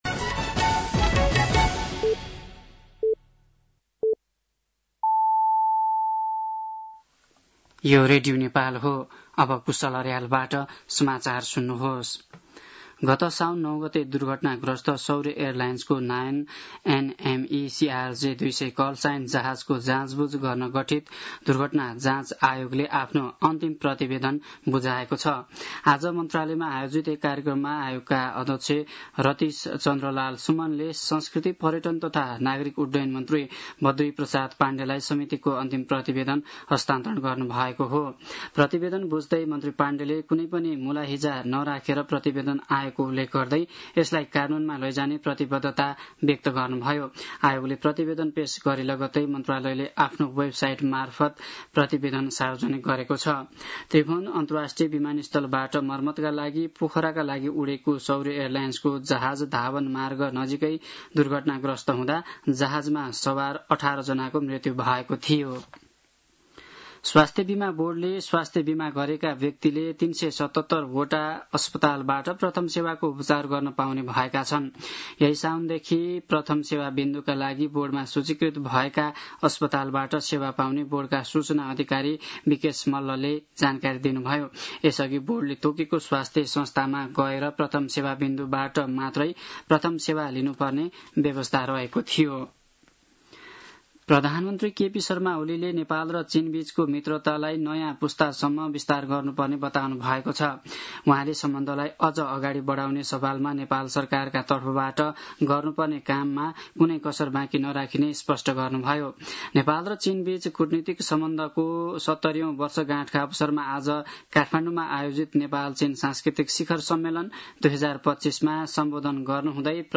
साँझ ५ बजेको नेपाली समाचार : २ साउन , २०८२
5-pm-nepali-news-4-02.mp3